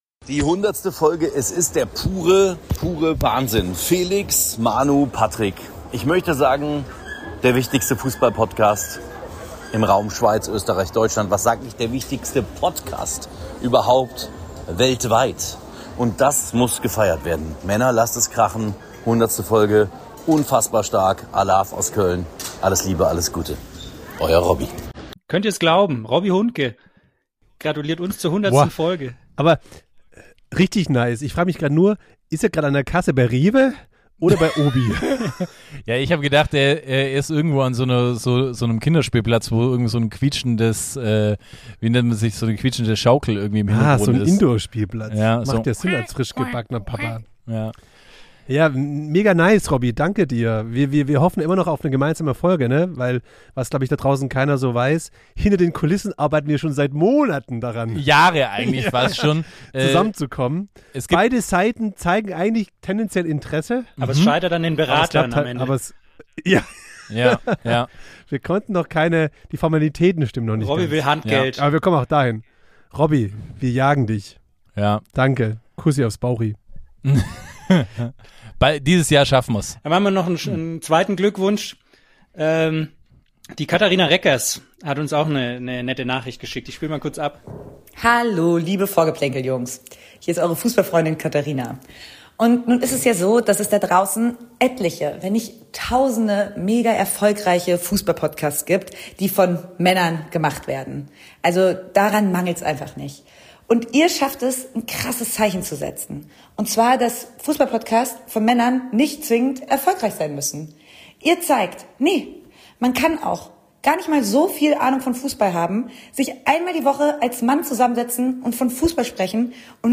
Vorgeplänkel klingt nach der Kneipe am Eck.
Nicht immer was für Feingeister, eher ungeschliffen, direkt, gelegentlich unlogisch und mit Halbwissen garniert.